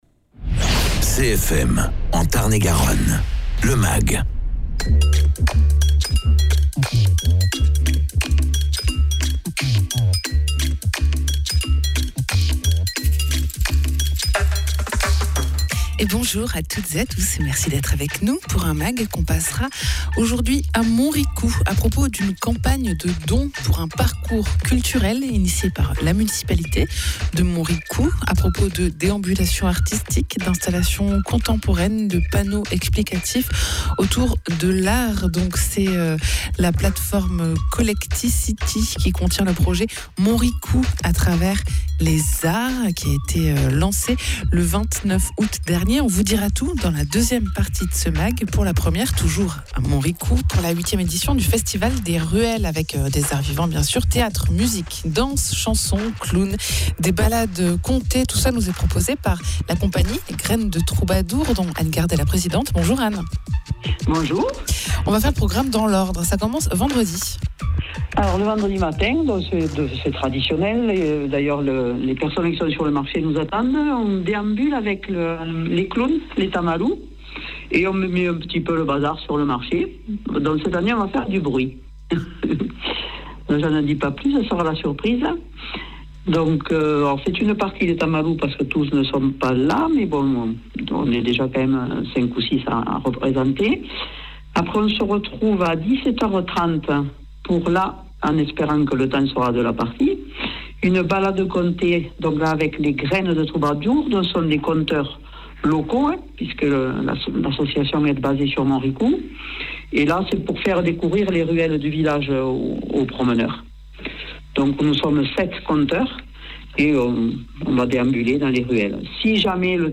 Michel Jannin, élu à Montricoux